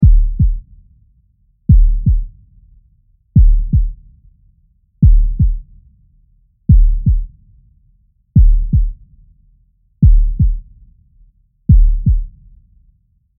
Звуки биения сердца
Вы можете скачать или слушать онлайн естественные ритмы: от размеренного стука для релаксации до учащенного пульса, создающего напряжение.
ВАРИАНТ 2 (ГРОМКО)